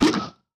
hit-v2.ogg